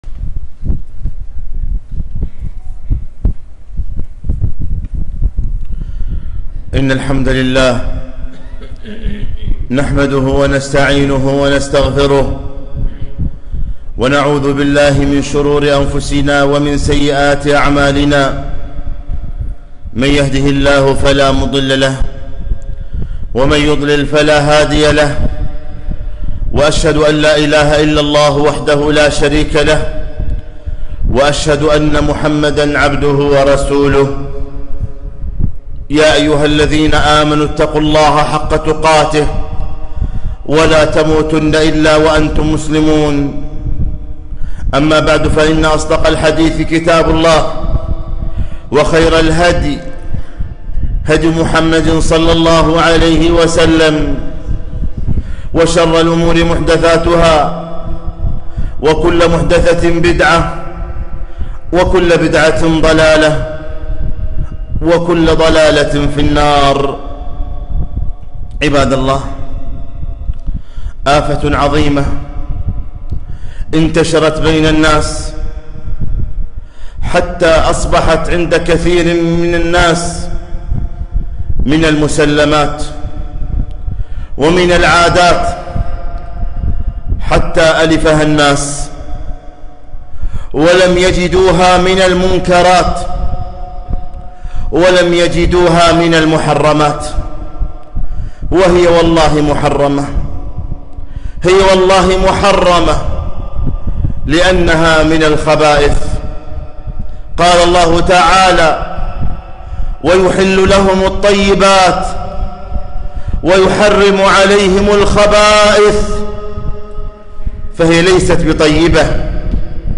خطبة - خطورة التدخين